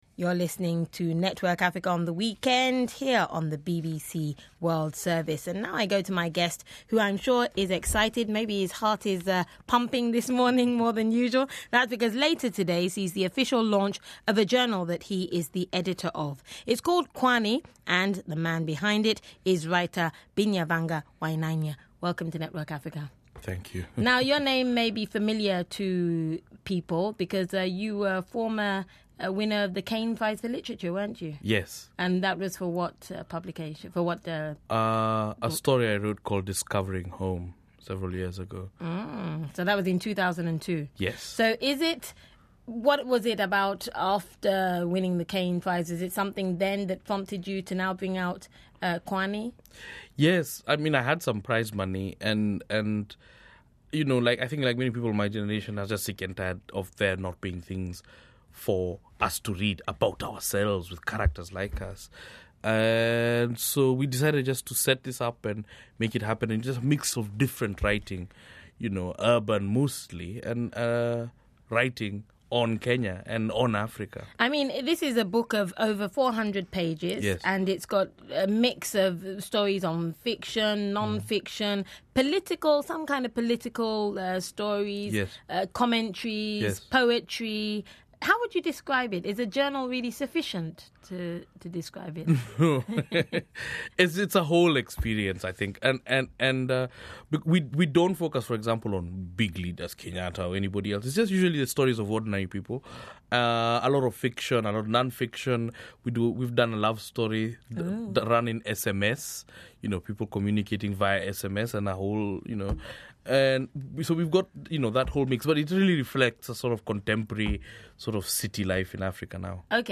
BBC World Service Interviews at the Beyond Borders Festival
The following interviews were broadcast on BBC Network Africa at the Weekend on BBC World Service to audiences across the African continent between October 2005 and March 2006.
live in the studio in Kampala